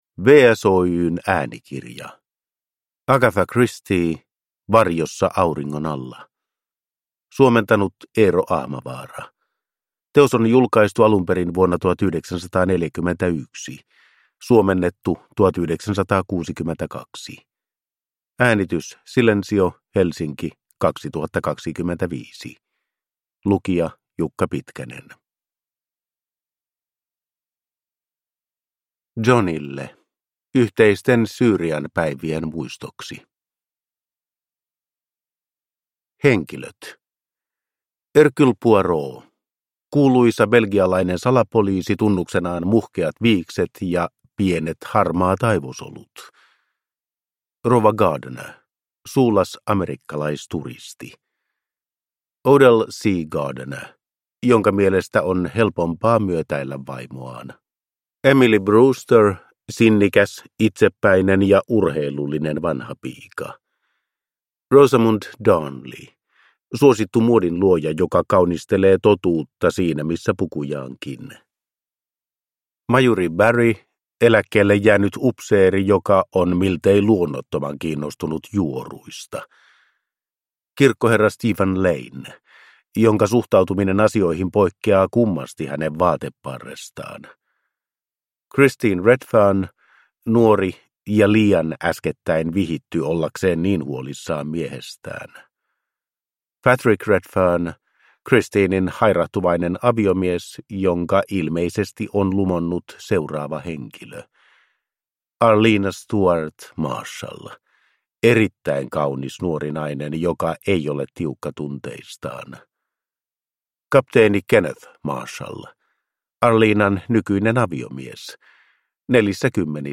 Varjossa auringon alla (ljudbok) av Agatha Christie